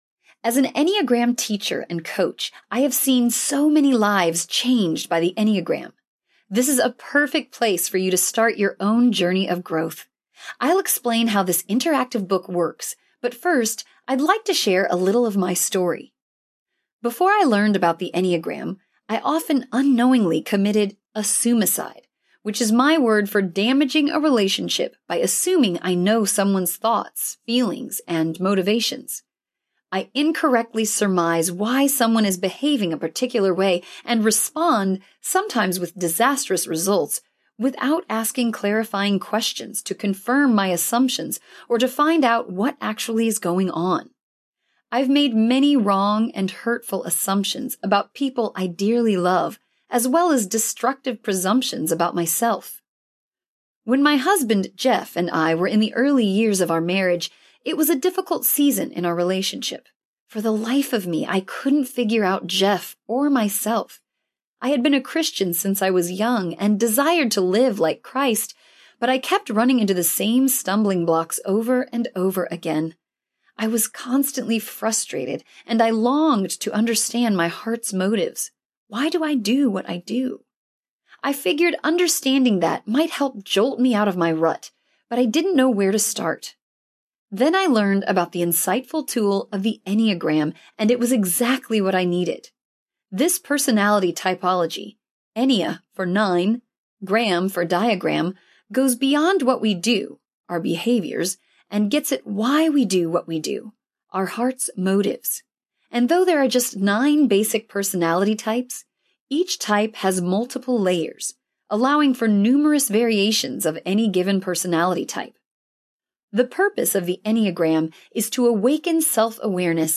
The Enneagram Type 2 (The Enneagram Collection) Audiobook
Narrator